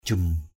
/ʥum/ 1.